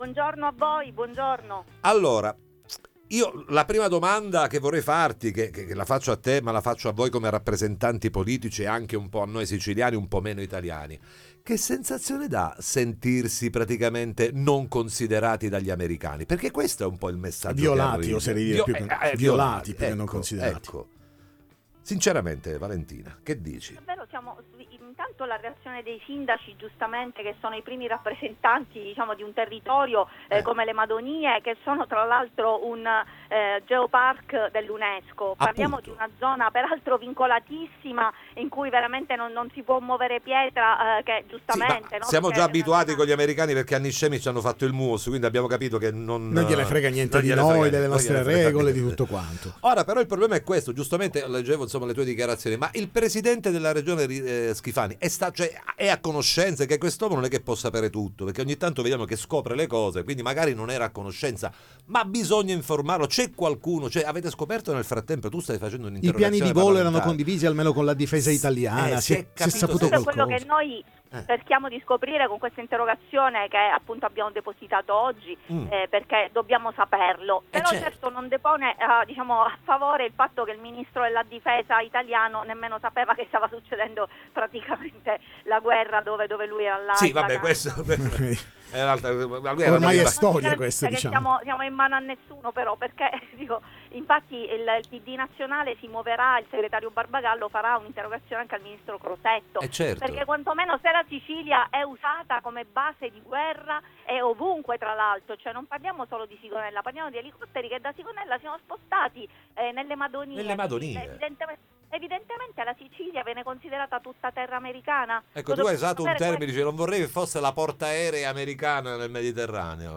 Due elicotteri americani atterrano nel Parco delle Madonie, ne parliamo con il dep. ARS Valentina Chinnici
Interviste